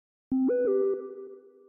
Games
Fears To Fathom Notification Sound